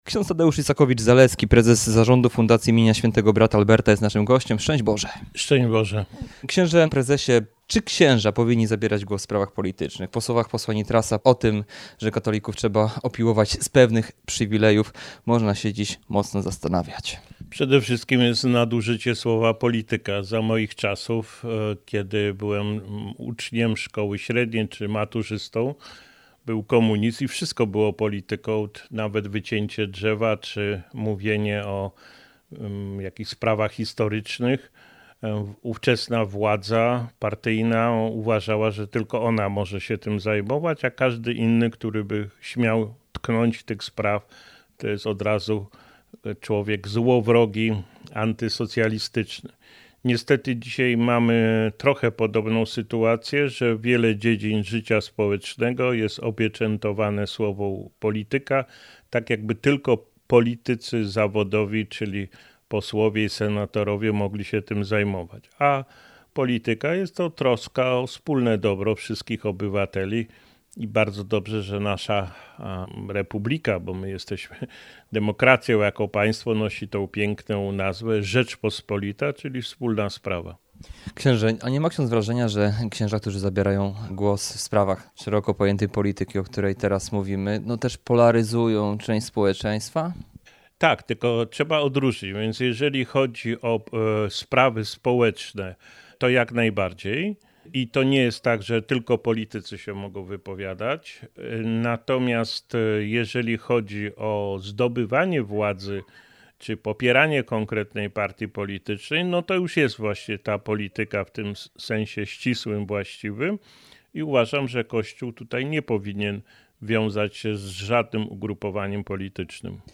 We wrześniu 2021 r. spotkaliśmy się we Wrocławiu podczas jubileuszu koła Towarzystwa Pomocy im. Brata Alberta przy parafii franciszkanów al. Kasprowicza.